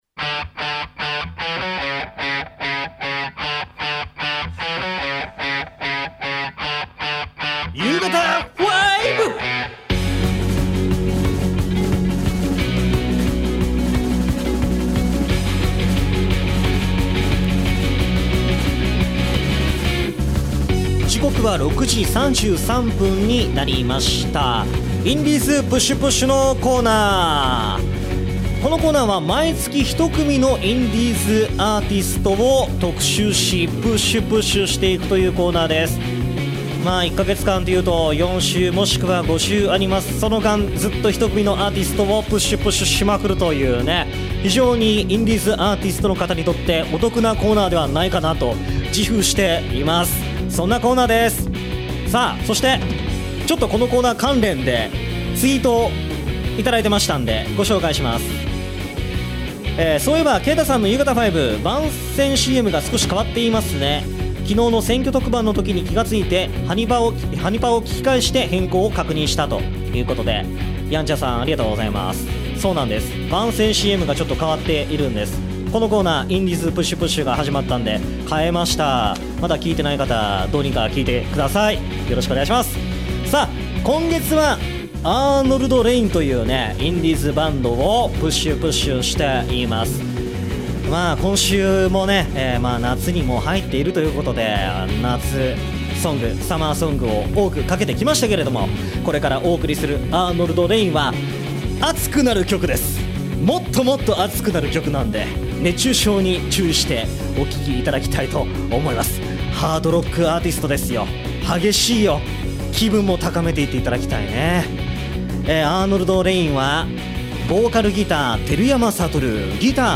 今週の放送同録音源はこちら↓